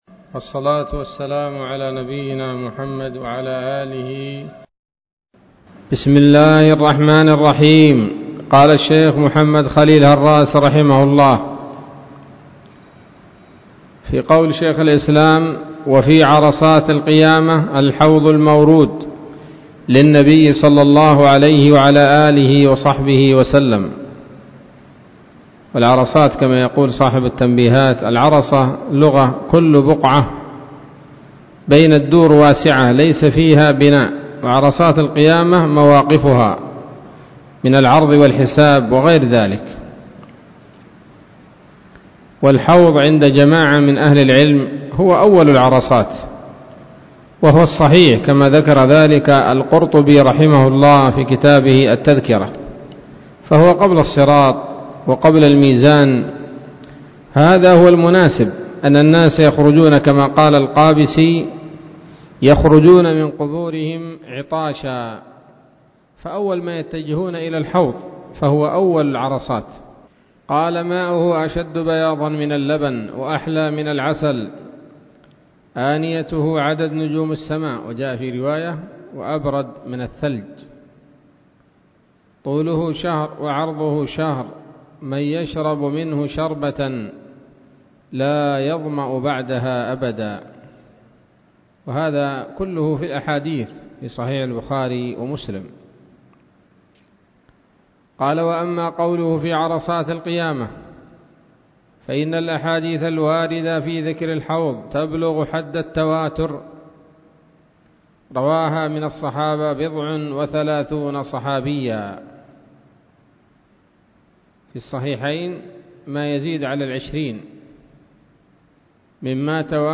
الدرس السابع والتسعون من شرح العقيدة الواسطية للهراس